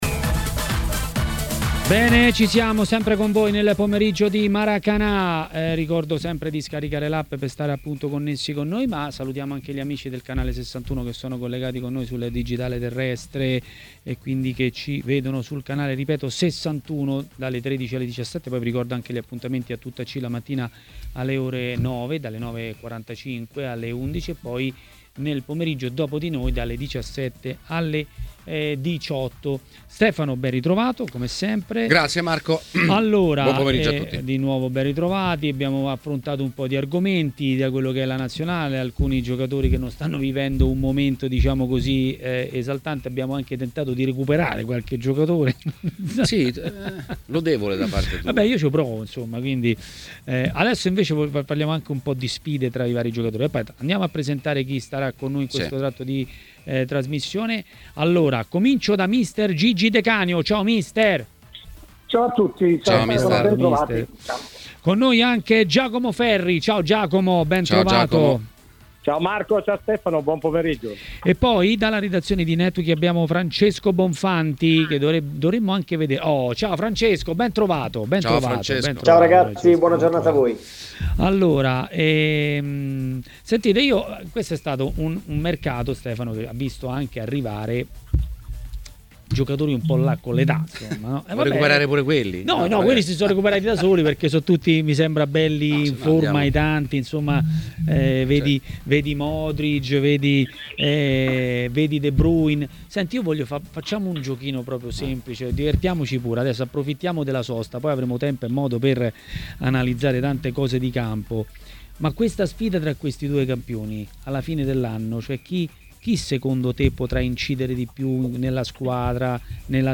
Le Interviste
Mister Luigi De Canio è stato ospite di TMW Radio, durante Maracanà: